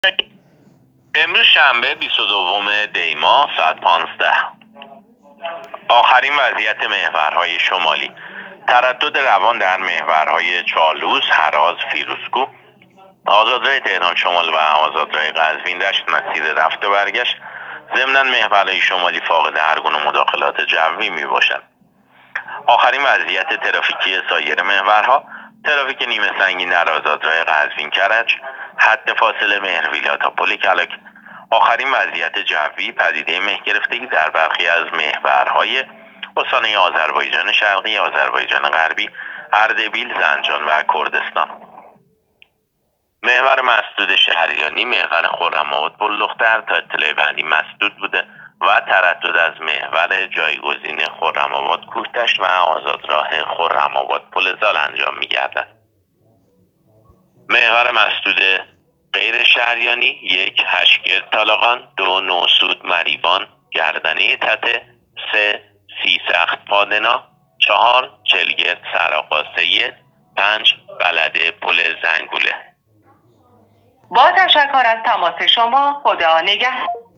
گزارش رادیو اینترنتی از آخرین وضعیت ترافیکی جاده‌ها تا ساعت ۱۵ بیست‌ودوم دی؛